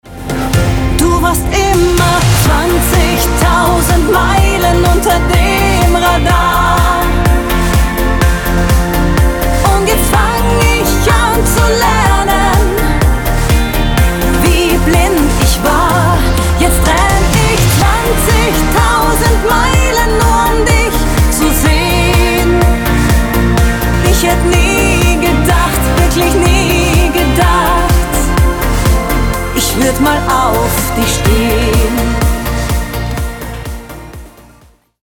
Mal sanft, mal mit Vollgas
Genre: Schlager